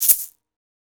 Index of /musicradar/essential-drumkit-samples/Shaken Perc Kit
Shaken  Egg Shaker 02.wav